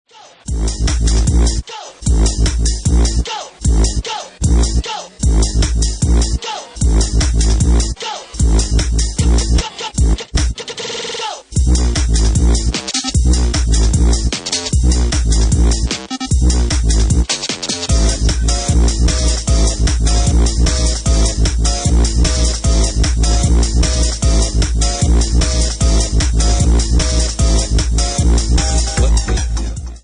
Genre:4x4 / Garage